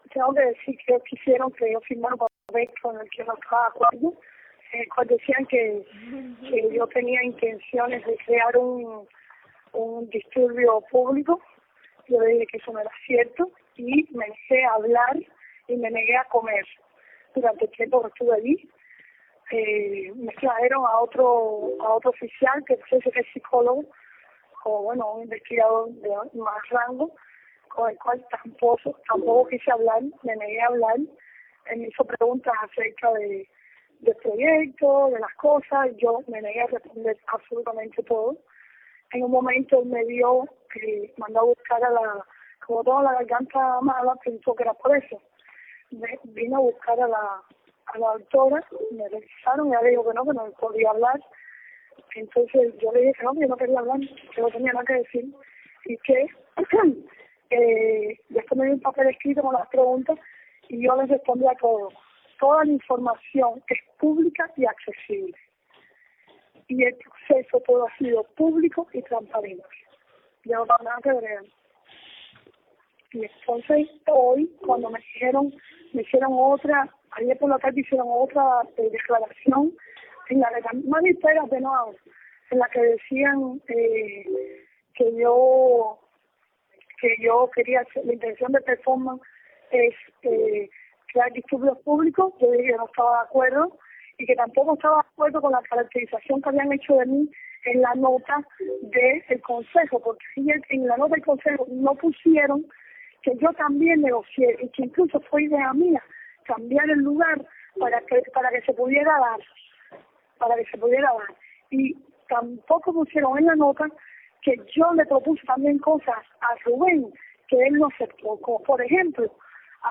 Tania Bruguera cuenta lo sucedido durante su detención